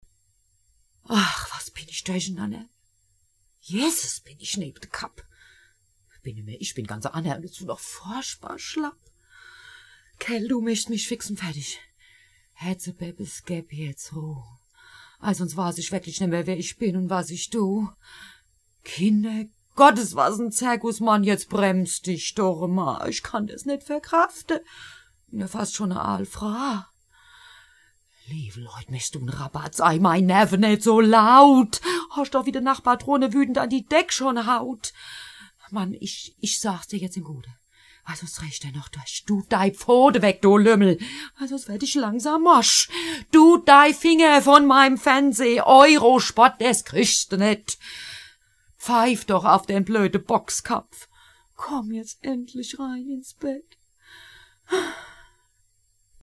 Schauspielern, Moderatorin, Sprecherin. Synchronsprecherin. Hörbuch-Sprecherin.
Sprechprobe: Sonstiges (Muttersprache):